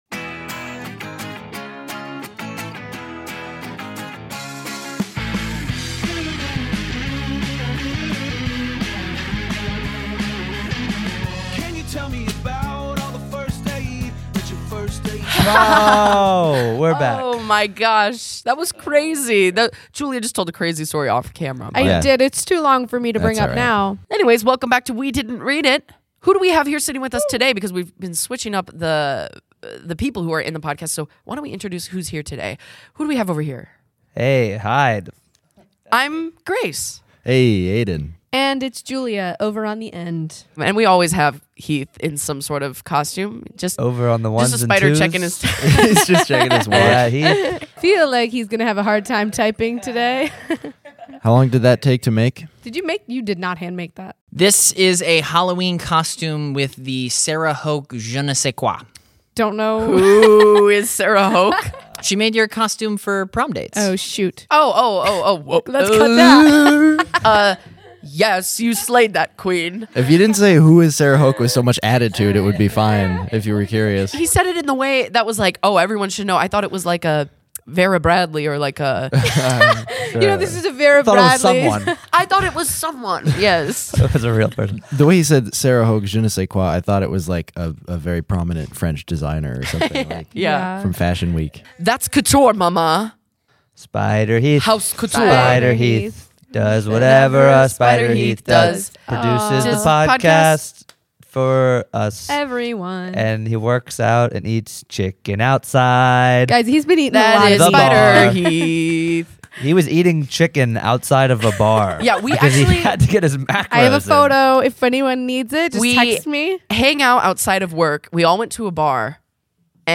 The comedic masterminds from American High Shorts and College Life are here to give "Charlotte's Web" a wild, unscripted makeover. Join the crew as they turn this beloved children's classic into a laugh-out-loud improvisational adventure.